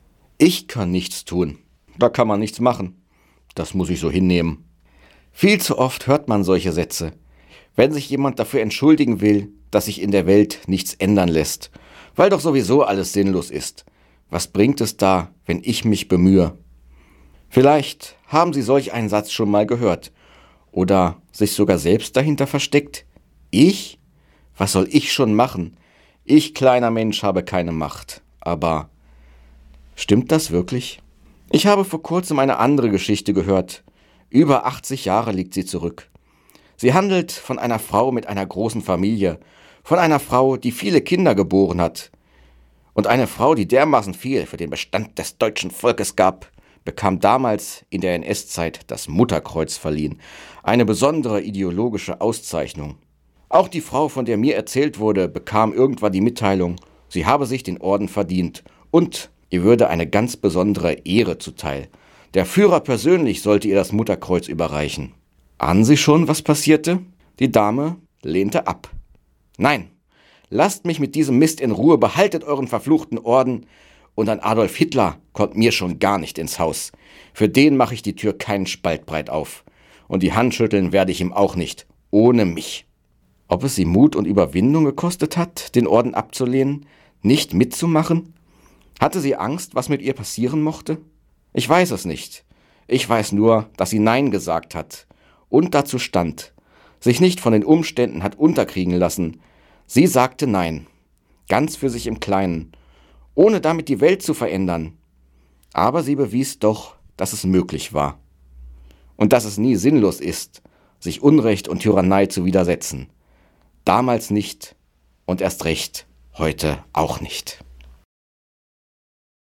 Radioandacht vom 19. Februar